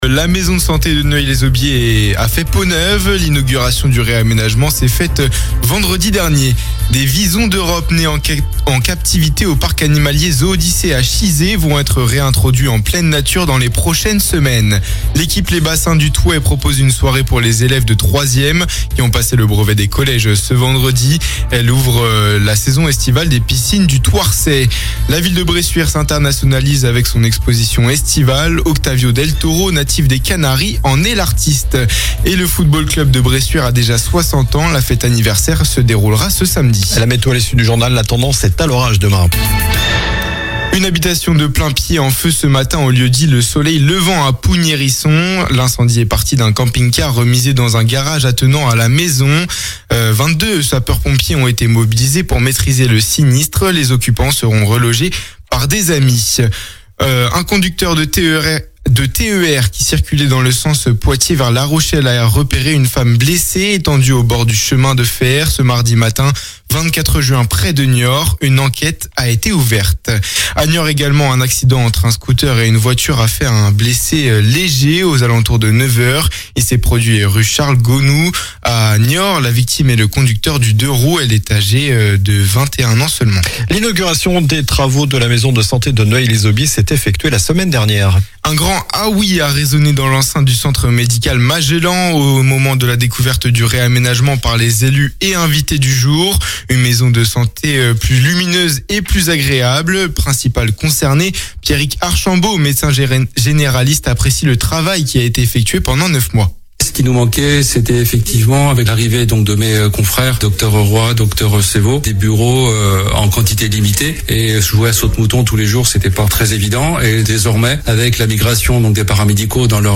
Journal du mardi 24 juin (soir)